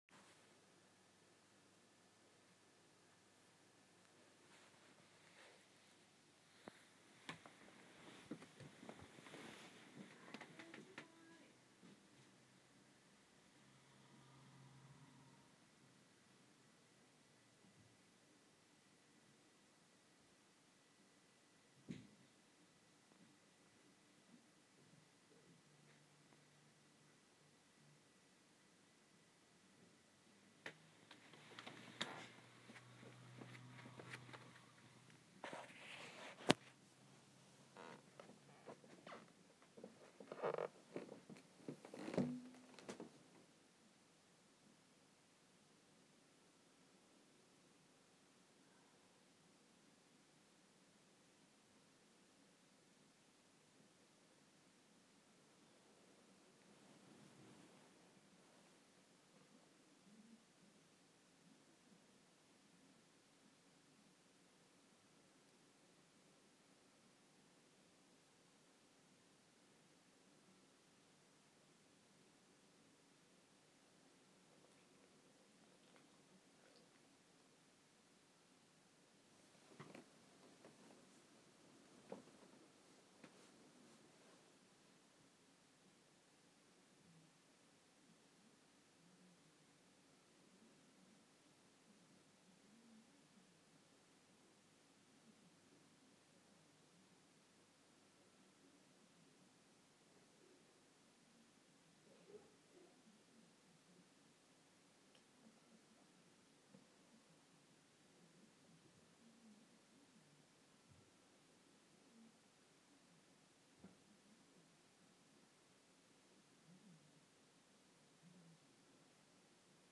zeb licking in his sleep